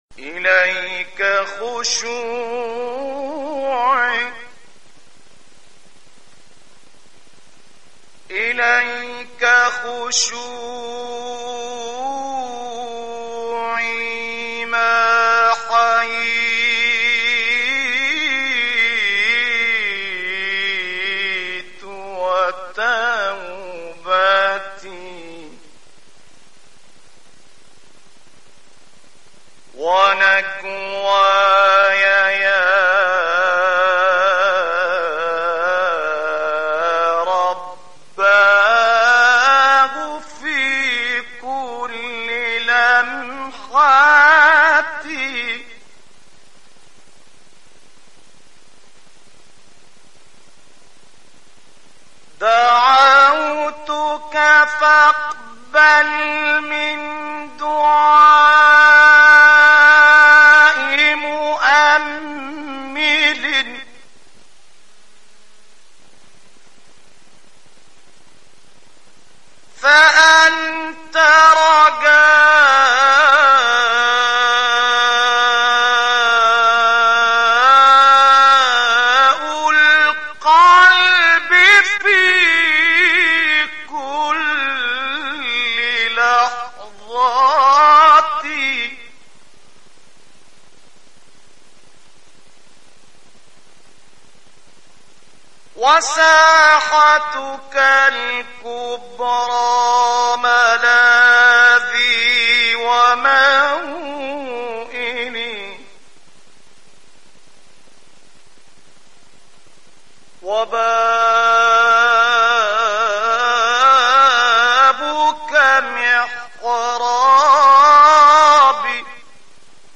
الشيخ نصر الدين طوبار (1920 - 16 نوفمبر 1986) قارئ قرآن ومنشد ديني مصري، من مواليد المنزلة بمحافظة الدقهلية.
ابتهالات الشيخ نصر الدين طوبار | اليك خشوعي ÇÓÊãÇÚ